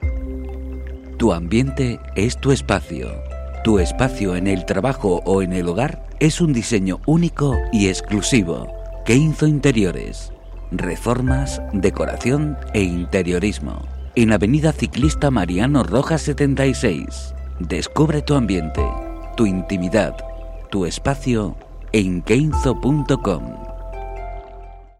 Soy locutor profesional con más de 30 años de experiencia.
Sprechprobe: Werbung (Muttersprache):